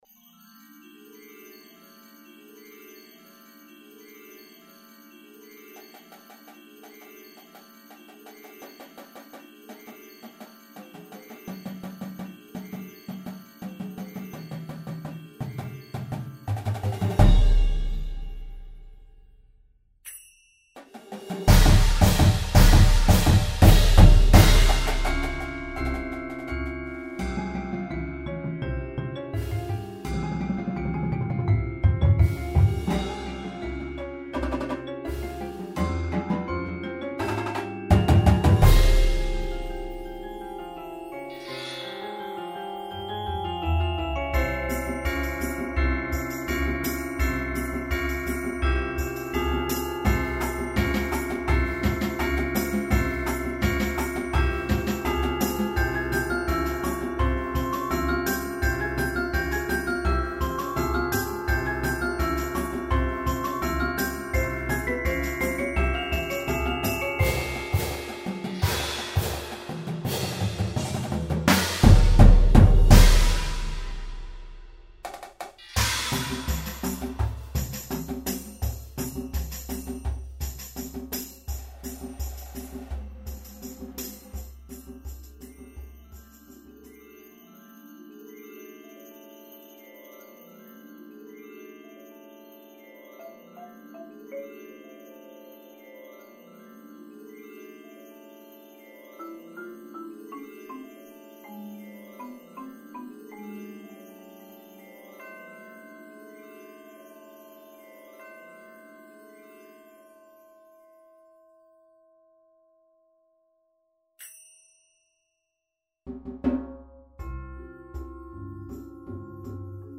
Snares
Tenors (Quints)
Bass Drums (4)
Marching Cymbals
Bells
Xylophone
Vibraphone
Marimba
Chimes
Bass Guitar
Synth (Piano)
Auxiliary Percussion 1, 2, 3